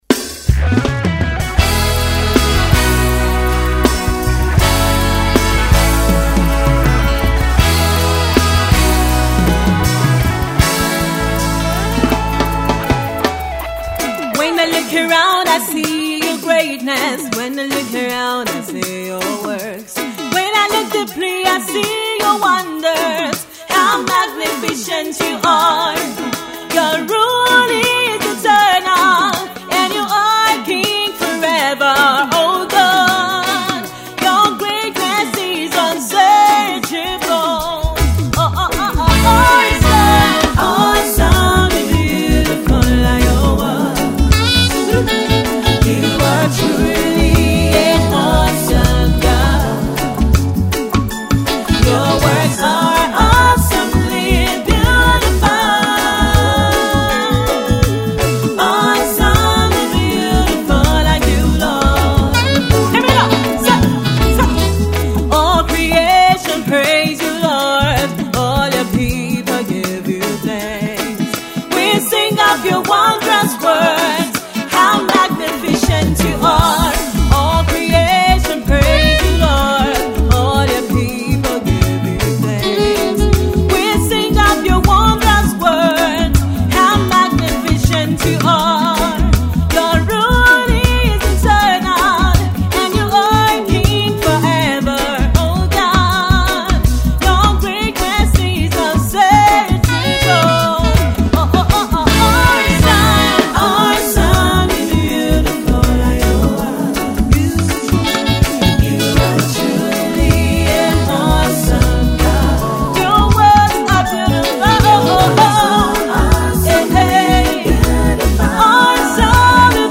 is a gospel reggae melody